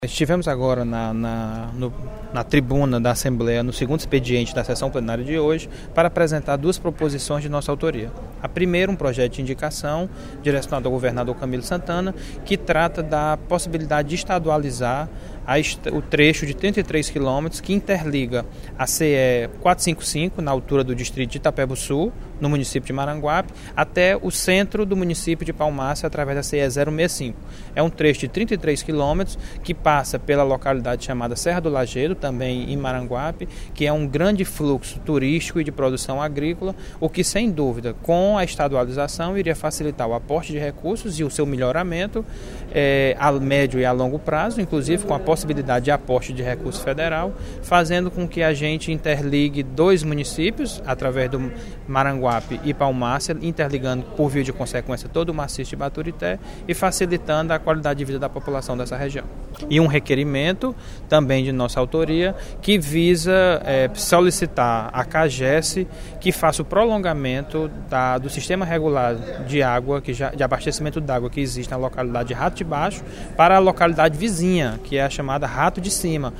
O deputado George Valentim (PCdoB) destacou, no segundo expediente da sessão plenária desta quarta-feira (15/06), um projeto de indicação e um requerimento, de sua autoria, que se encontram em tramitação na Assembleia Legislativa do Ceará.